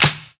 Snare (Question Mark).wav